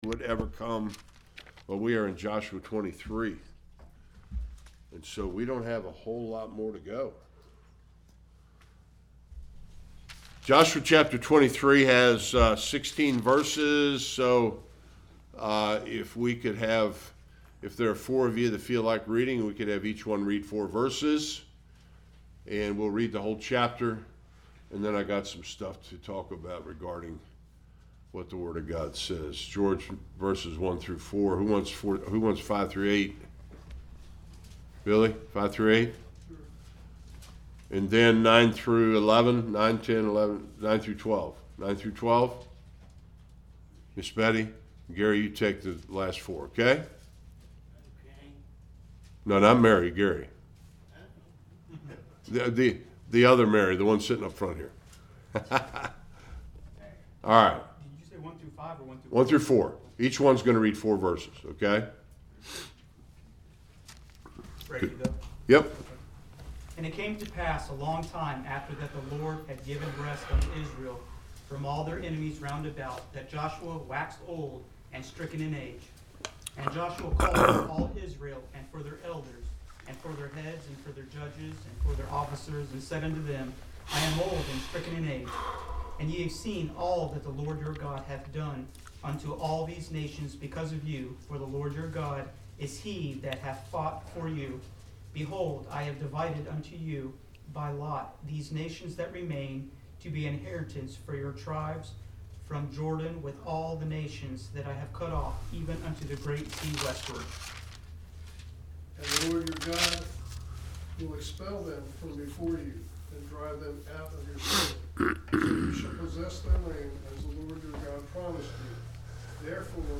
Joshua 23 Service Type: Sunday School Chapters 23 and 24 contain the consecration of the western tribes of Israel.